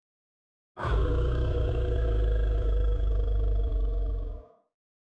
Lion1 Téléchargement d'Effet Sonore
Lion1 Bouton sonore